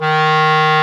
WND  CLAR 01.wav